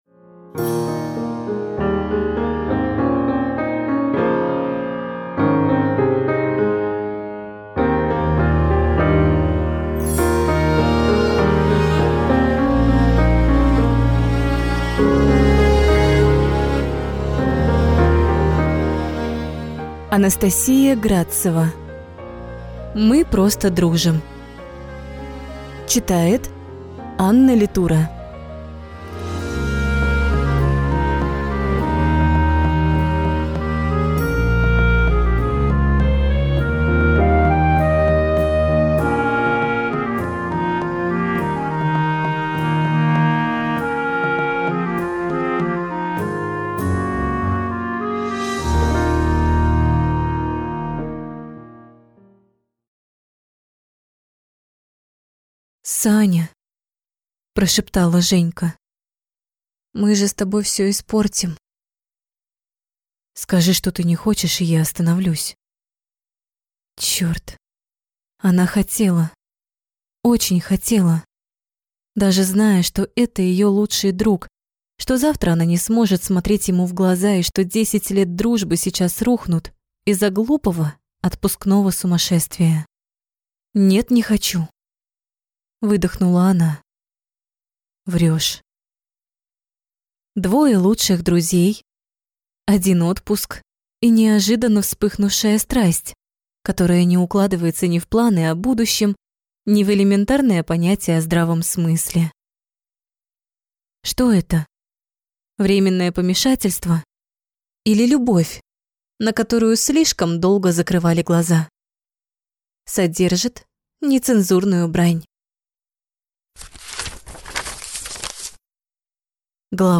Аудиокнига Мы просто дружим | Библиотека аудиокниг